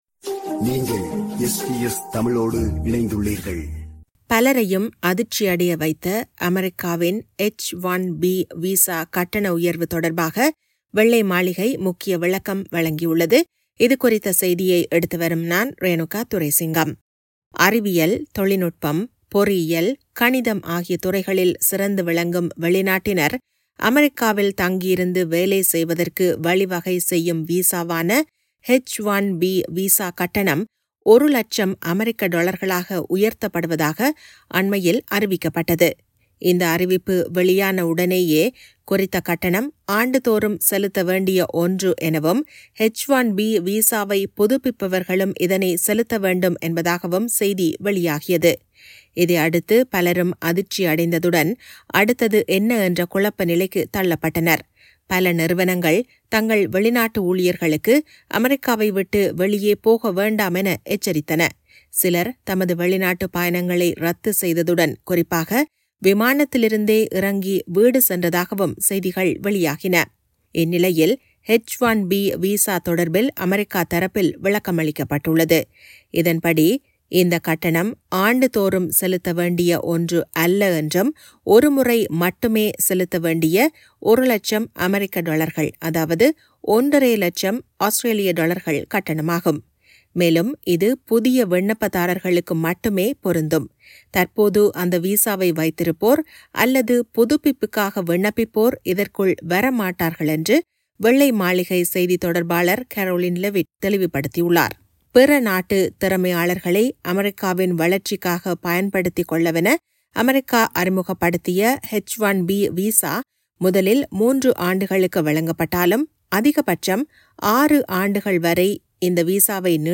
அமெரிக்காவின் H-1B விசா கட்டணம் 100,000 அமெரிக்க டொலர்கள் அதாவது 150,000 ஆஸ்திரேலிய டொலர்களாக உயர்த்தப்பட்டமை தொடர்பாக வெள்ளை மாளிகை முக்கிய விளக்கம் வழங்கியுள்ளது. இதுகுறித்த செய்தியைத் தருகிறார்